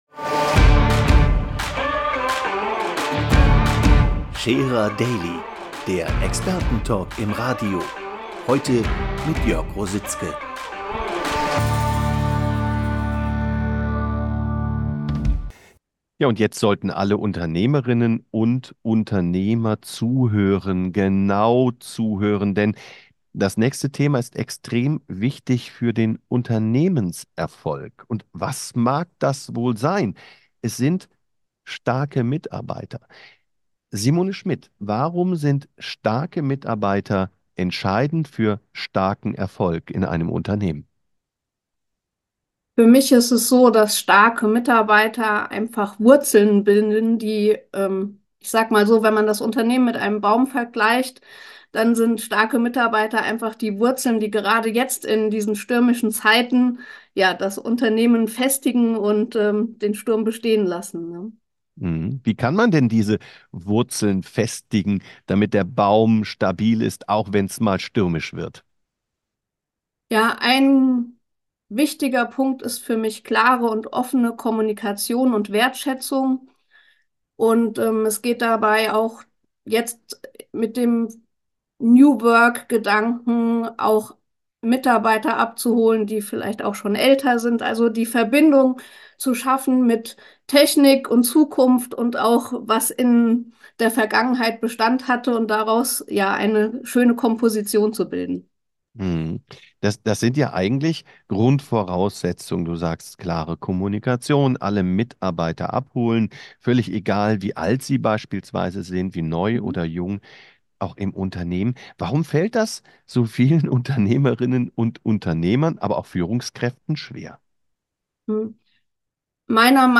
Radiointerview -